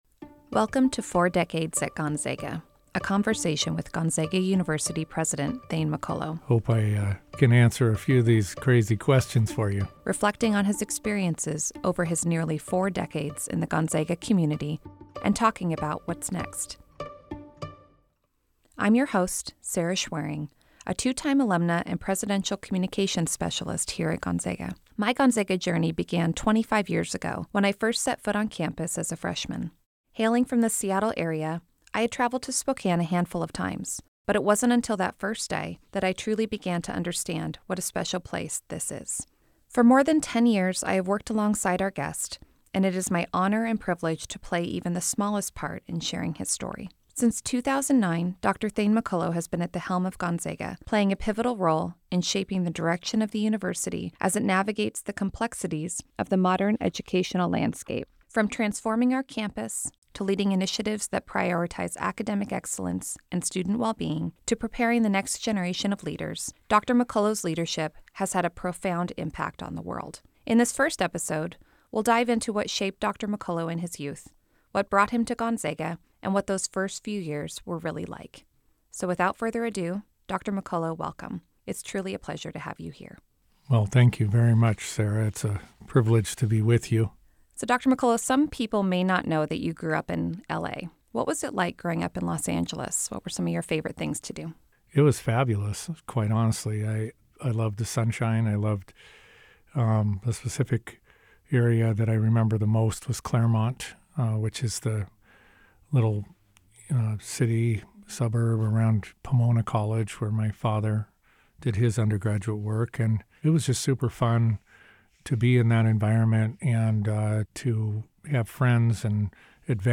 Four Decades at Gonzaga: A Conversation